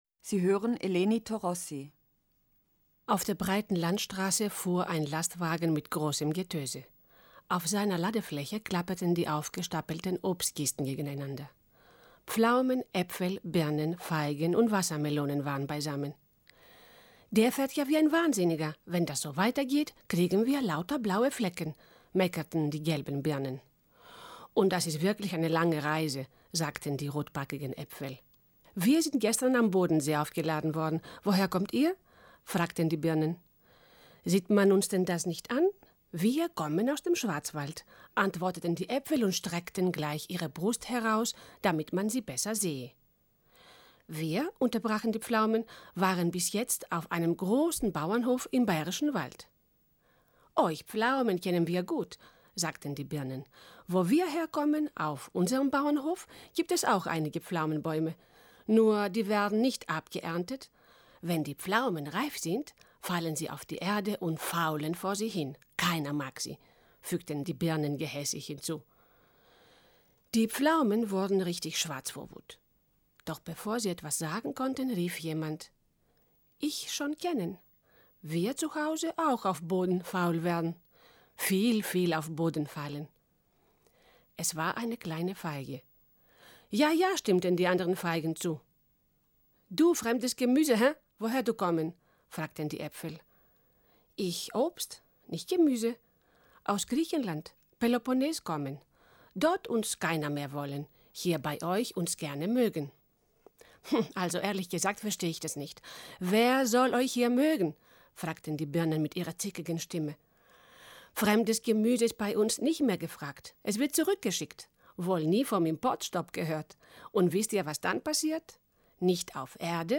Das Literaturtelefon-Archiv wird in der Monacensia im Hildebrandhaus aufbewahrt. Es umfasst 40 CDs, auf denen insgesamt 573 Lesungen enthalten sind.